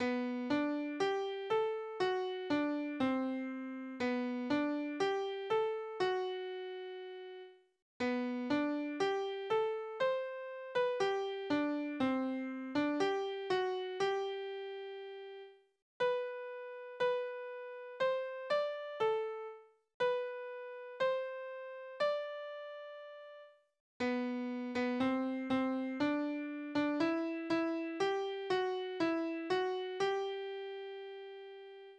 The basic tune
A simple WAV format version of this tune is available by clicking
Pleasingly, a Glee Club leader - they were among the first to try it out as a warm up - said: "The Song is easy to learn and appealing - a mix of traditional and modern in the tune, with words that are suitable for anyone".
The updated Song has the same basic tune - though with a few dotted crotchets towards the end to add a bit of a jaunty feel.